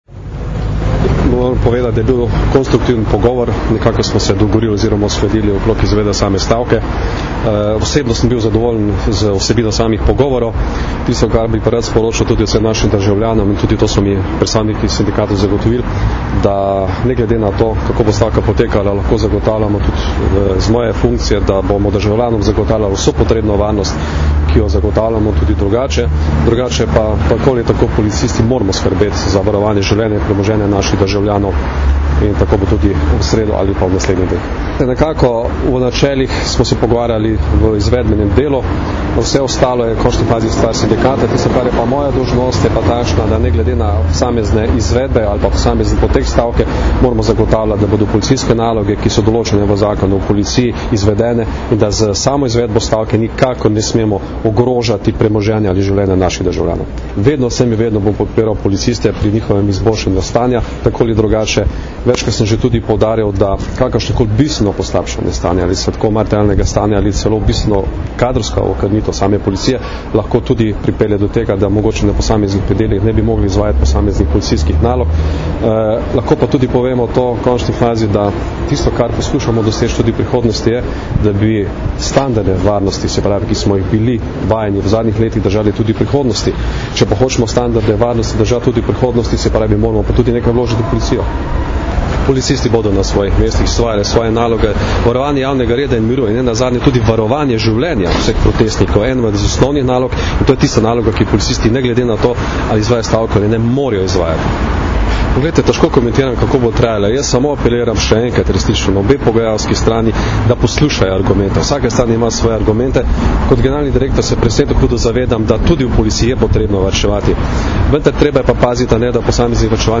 Zvočni posnetek izjave Janka Gorška (mp3)